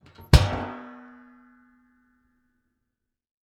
household
Garbage Can Lid Close